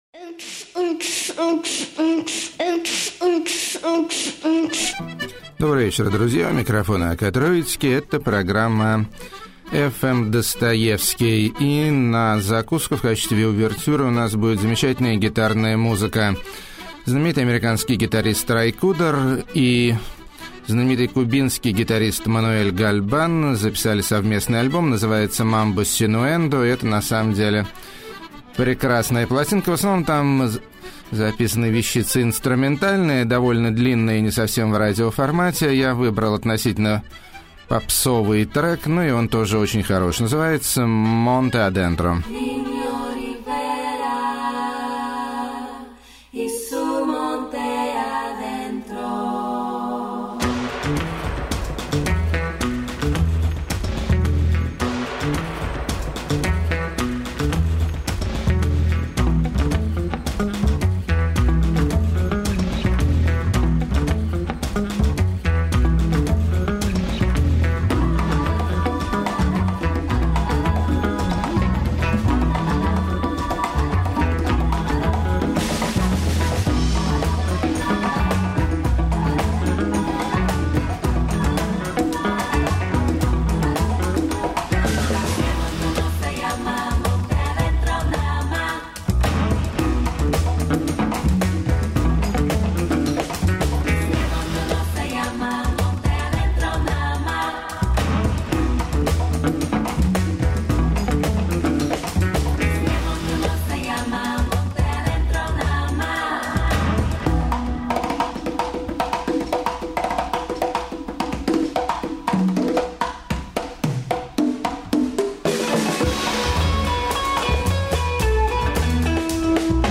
Mambo Surfin Twang!
Eels-ish Indie Psycho
Mutant Eurodisco Kitsch
Sci-fi Analogue Bubblegum
Balkan Music At Its Most Tragic
Female Country At Its Most Tragic
Electronic&elegant